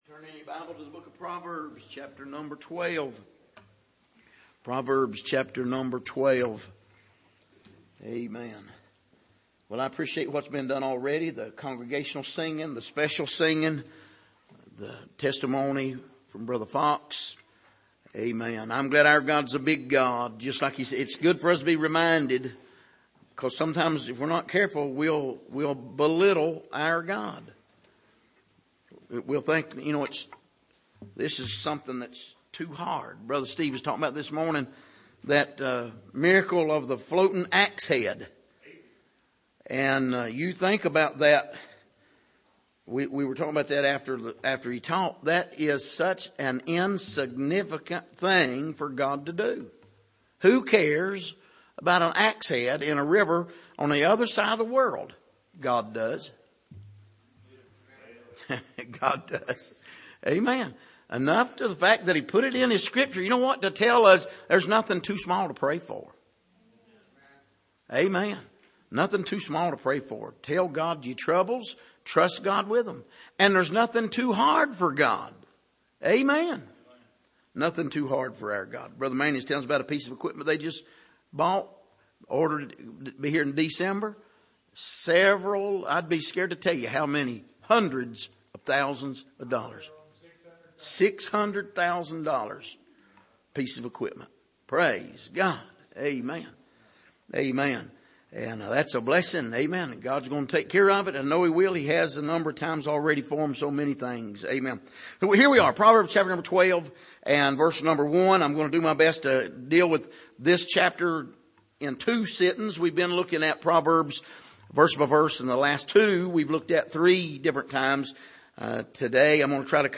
Passage: Proverbs 12:1-14 Service: Sunday Evening